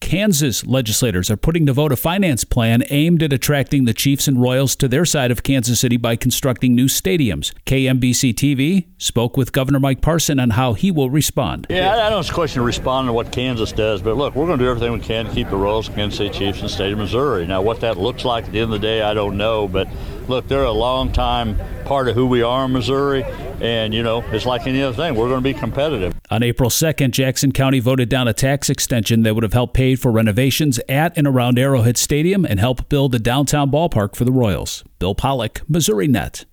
KMBC-TV spoke with Governor Mike Parson on how he will respond